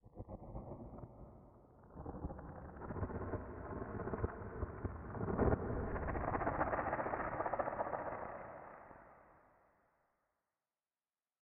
Minecraft Version Minecraft Version snapshot Latest Release | Latest Snapshot snapshot / assets / minecraft / sounds / ambient / nether / nether_wastes / addition2.ogg Compare With Compare With Latest Release | Latest Snapshot